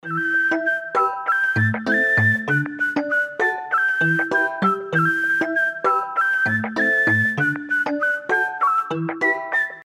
• Качество: 320, Stereo
позитивные
свист
веселые
без слов
дудка